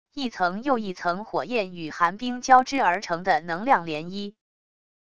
一层又一层火焰与寒冰交织而成的能量涟漪wav音频